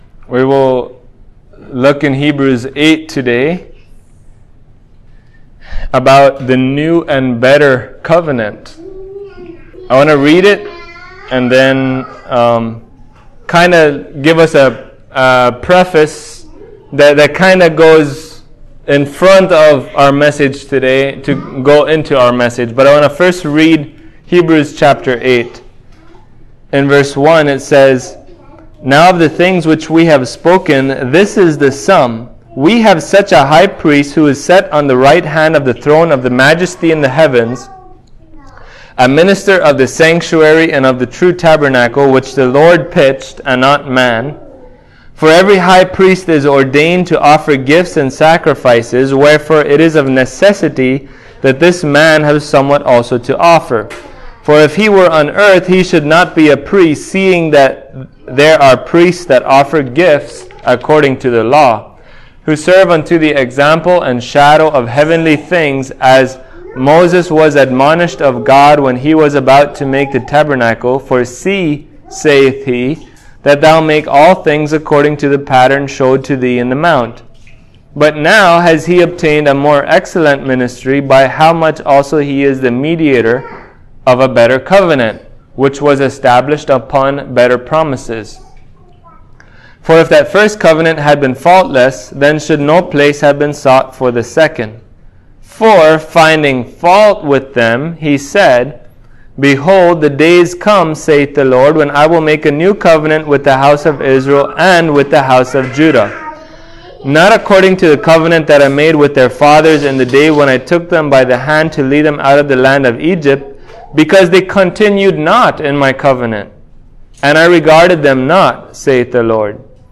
Listen to this sermon for answers to those questions and more.
Hebrews 8:1-13 Service Type: Sunday Morning Is the New Covenant really a better covenant?